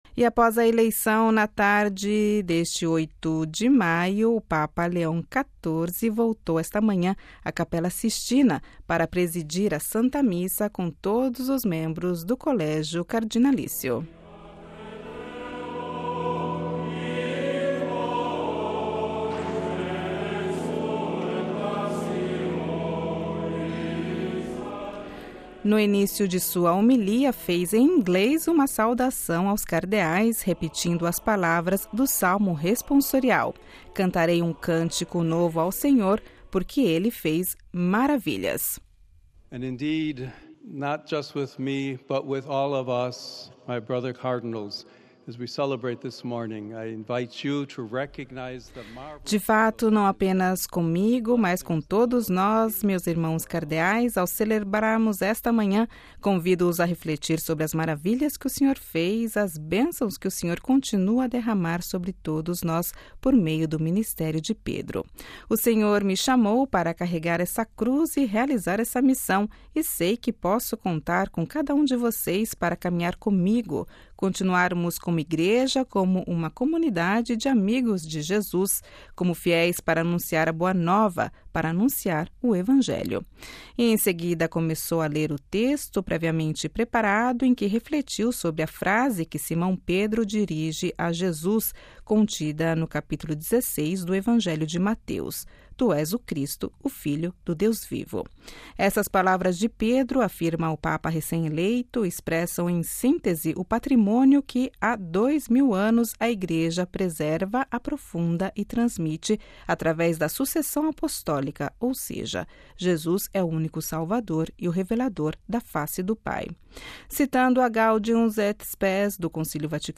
Após a eleição na tarde de 8 de maio, o Papa Leão XIV voltou esta manhã à Capela Sistina para presidir à Santa Missa com todos os membros do Colégio Cardinalício.
No início de sua homilia, fez em inglês uma saudação aos cardeais, repetindo as palavras do Salmo responsorial: “Cantarei um cântico novo ao Senhor, porque ele fez maravilhas”.